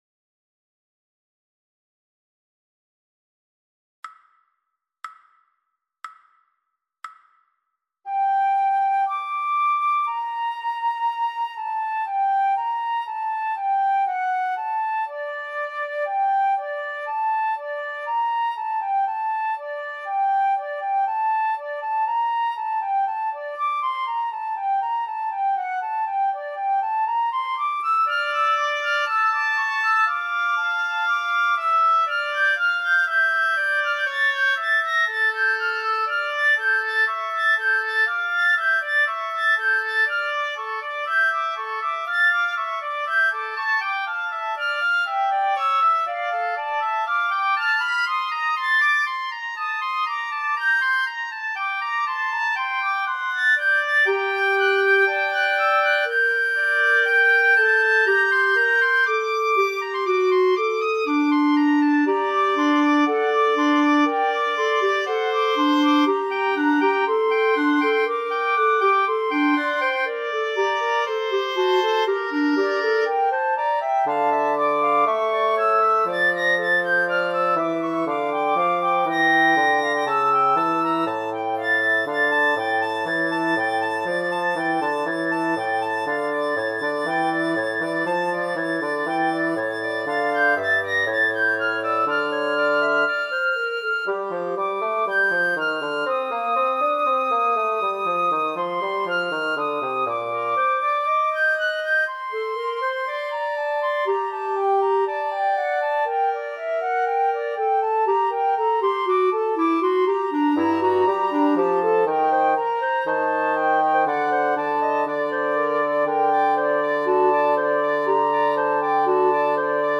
Bach Fugue in Gm - medium.mp3